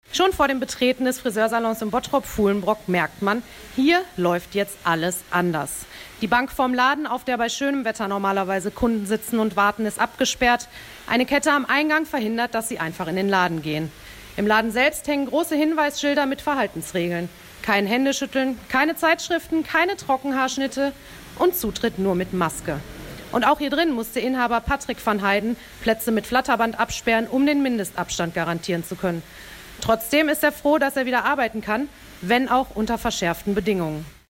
reporterin-im-friseursalon.mp3